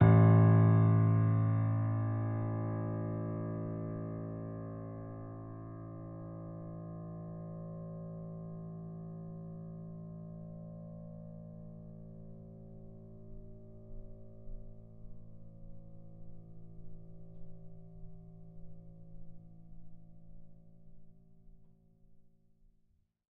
sampler example using salamander grand piano
A1.ogg